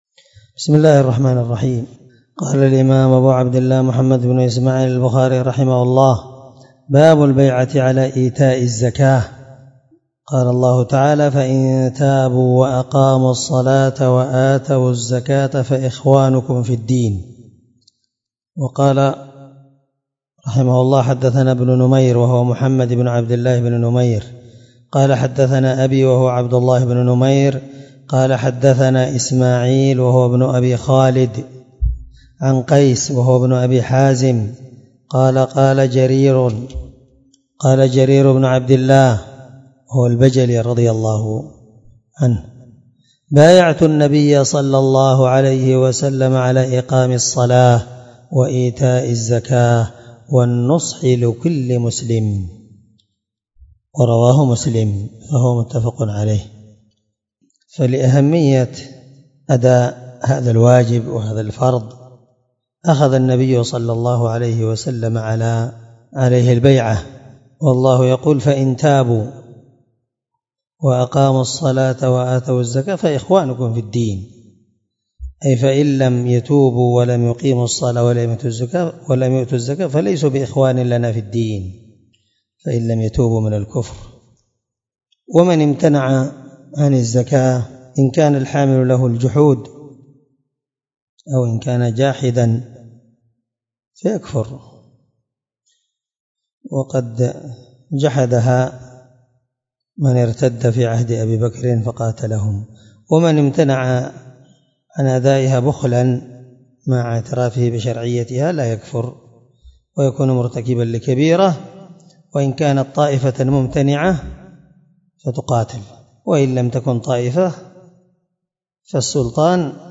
الدرس 4من شرح كتاب الزكاة حديث رقم(1401 )من صحيح البخاري